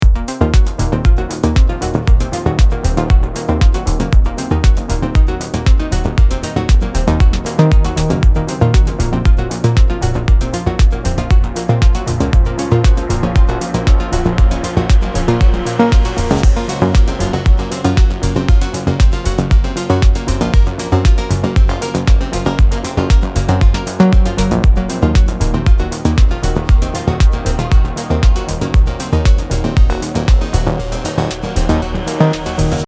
Тоже типа техно ))
Послушал на тб2 - бочки реально много.